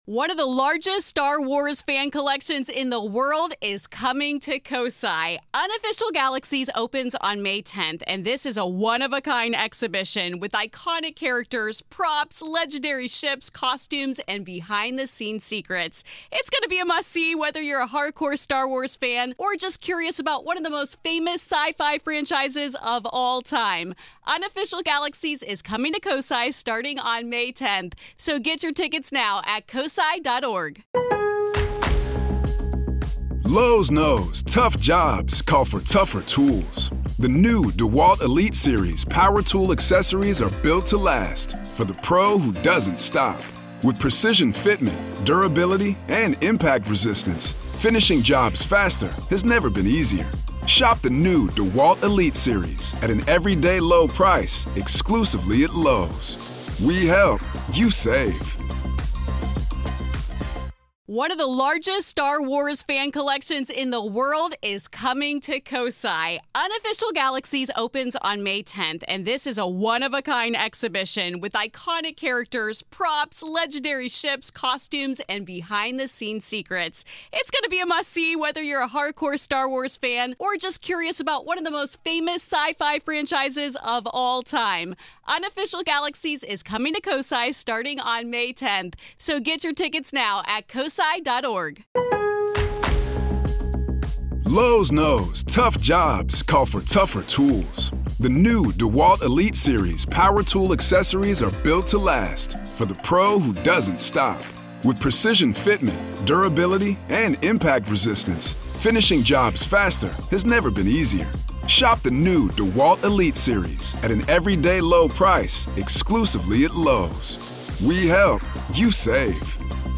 I am joined by former head of AATIP, Luis Elizondo to discuss ;